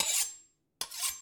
x_enchanting_disenchant.ogg